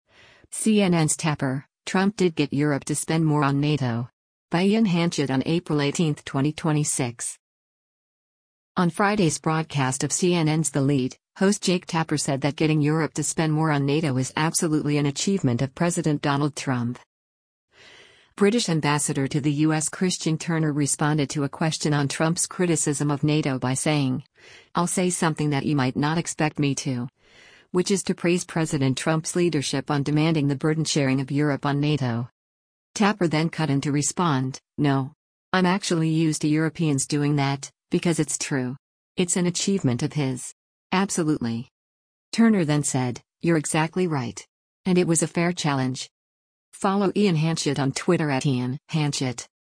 On Friday’s broadcast of CNN’s “The Lead,” host Jake Tapper said that getting Europe to spend more on NATO is “Absolutely” “an achievement of” President Donald Trump.